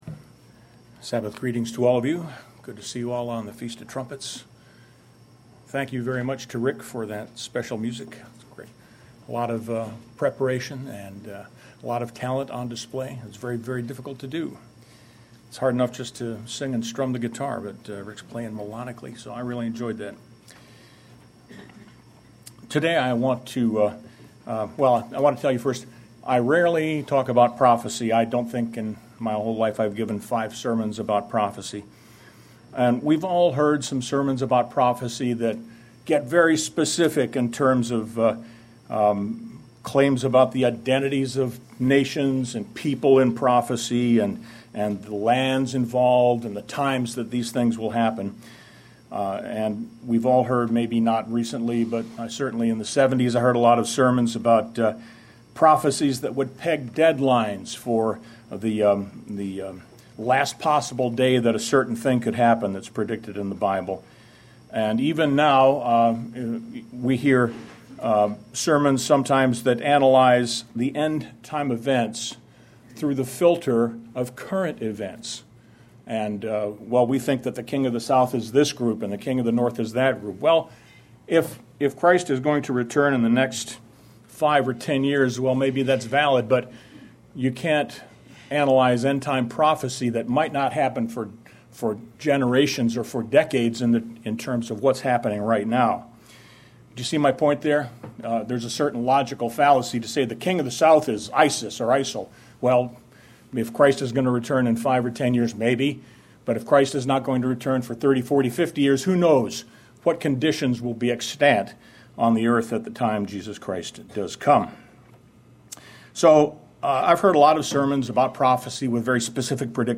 As the 1,000-year reign of Jesus Christ begins, will all people immediately give their allegiance to the King of Kings? This sermon shows that the adoption of God's laws and rule will very likely be gradual, and the Kingdom of God will spread to some nations gradually, in fulfillment of Matthew 13:33.